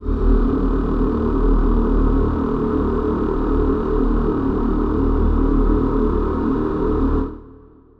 Choir Piano (Wav)
D#1.wav